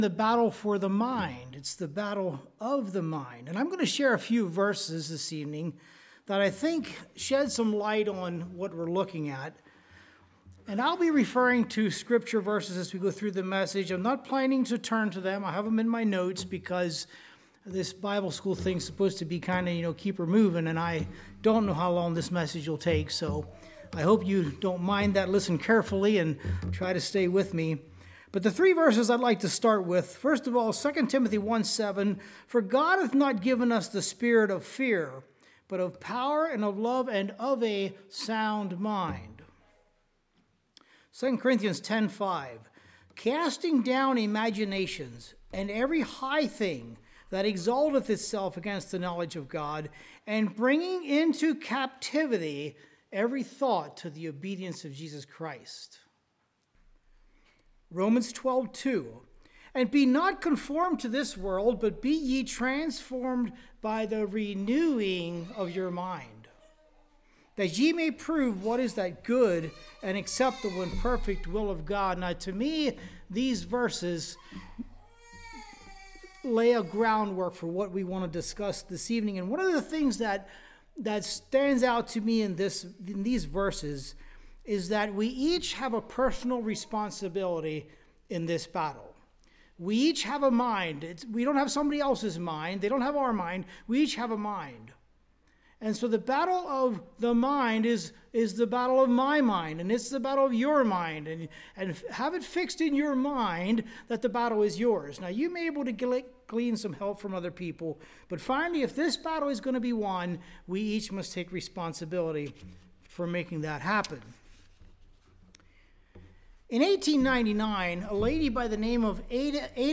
Congregation: Susquehanna Valley